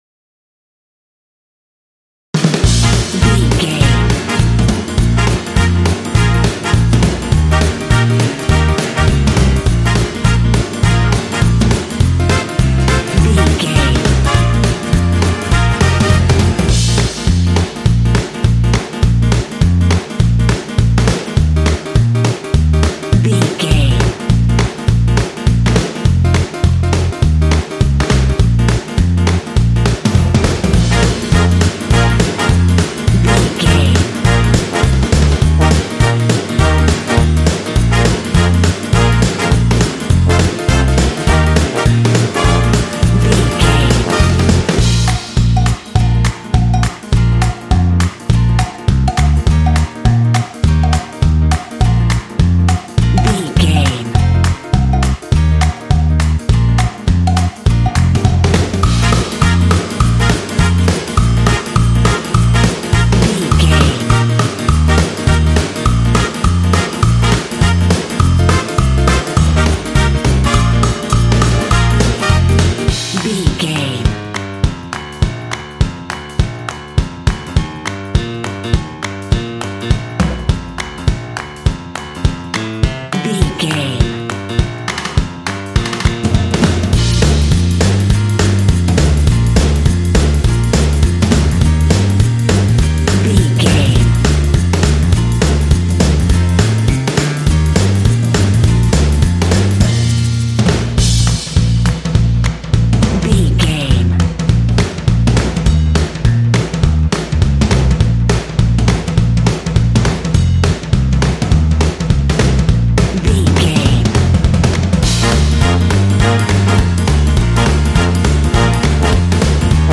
Ionian/Major
B♭
driving
happy
energetic
groovy
lively
piano
bass guitar
drums
horns
percussion
50s
jazz